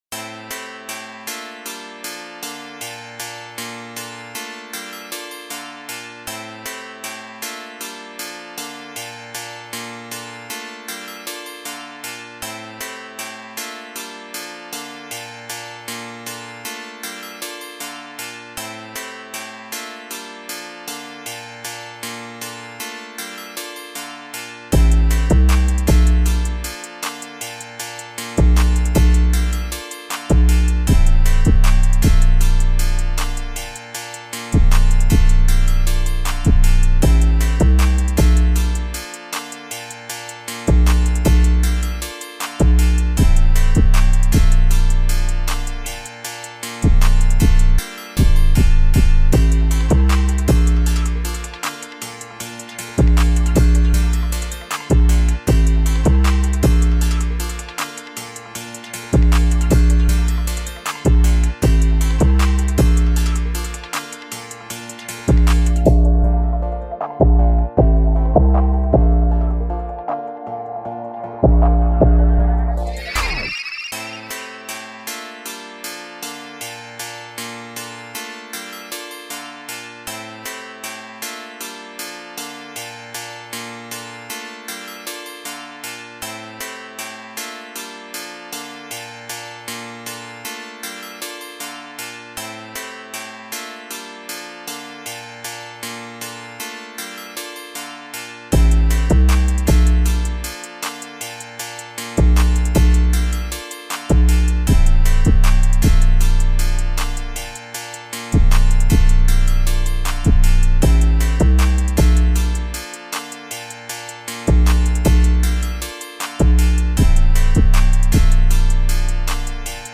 official instrumental
2025 in Hip-Hop Instrumentals